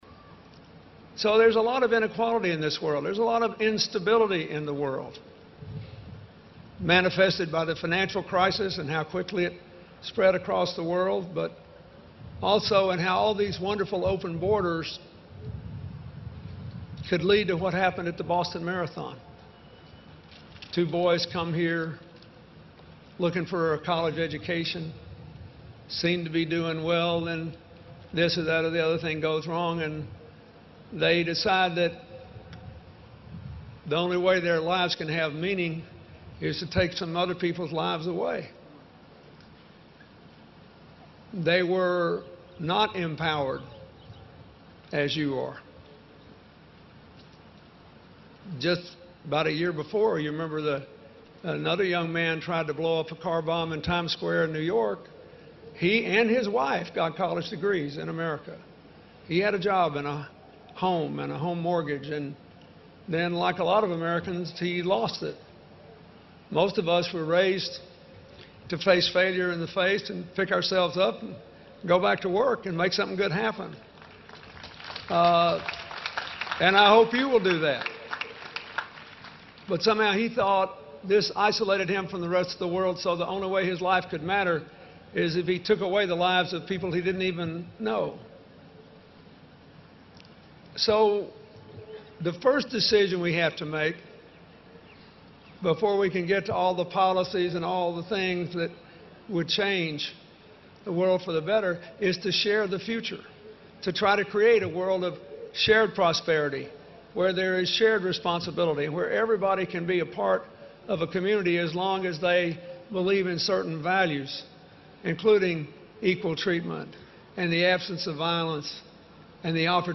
公众人物毕业演讲 第127期:2013年克林顿在霍华德大学(6 听力文件下载—在线英语听力室